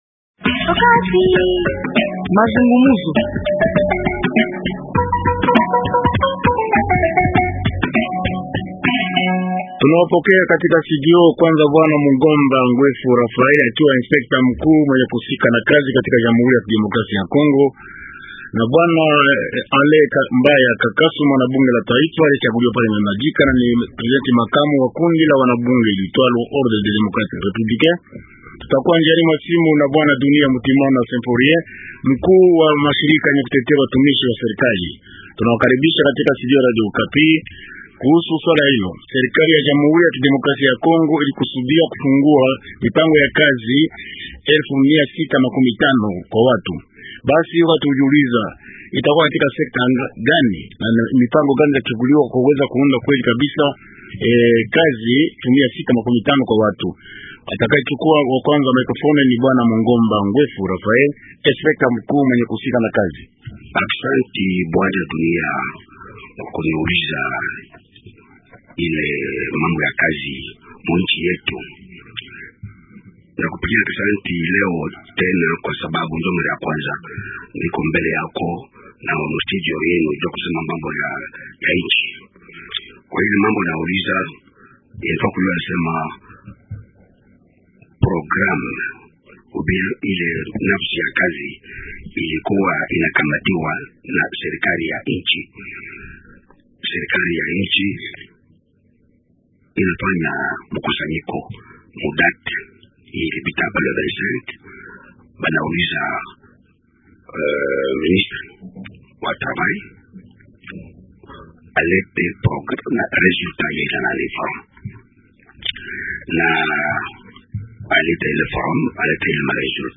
Wajumbe wa upinzani , wa kundi lenyi kuwa madarakani na wa shirika la rahiya wanabadirishana maoni kuhusu jambo lile. rnWaalikwa :